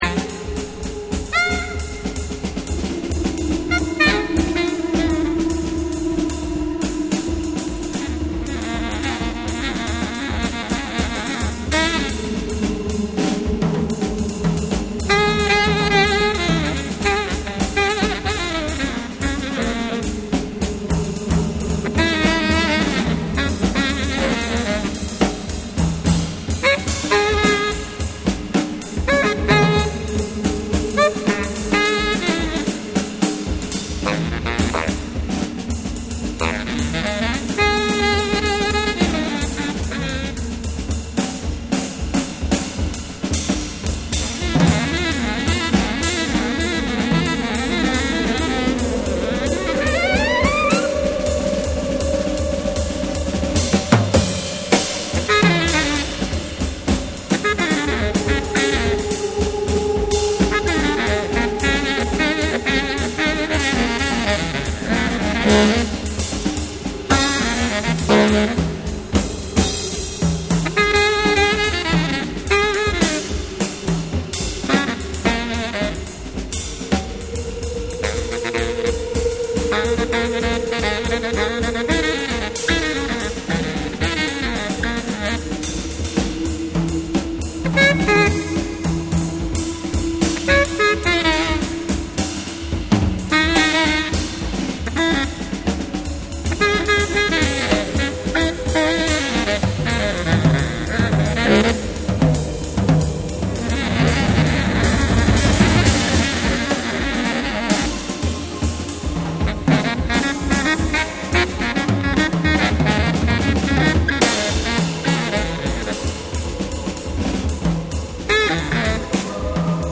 ジャズ、エレクトロニクス、詩的な語りが溶け合う、まさにECMならではの冬の記憶。